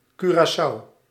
Curaçao[1] (/ˈkjʊərəs, -s/ KURE-ə-sow, -soh, Dutch: [kyːraːˈsʌu]
Nl-curaçao.ogg.mp3